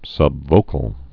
(sŭb-vōkəl)